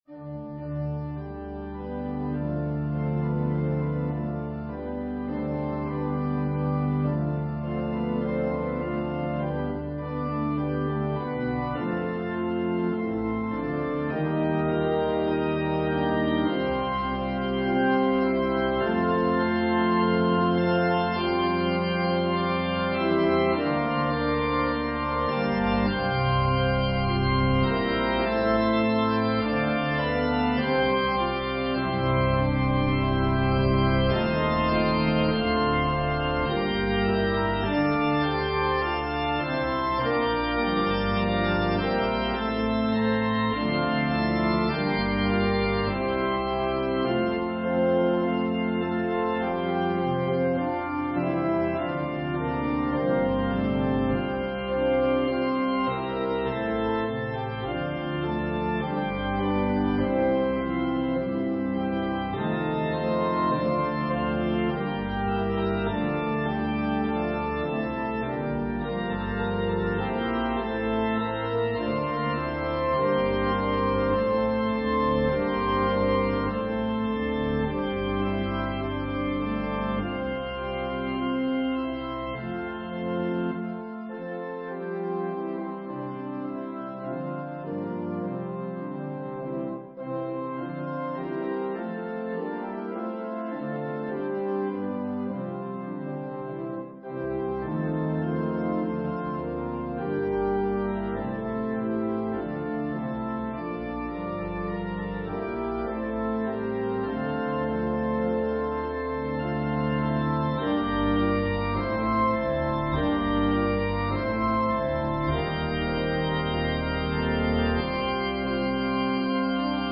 An organ solo version
fine British hymn.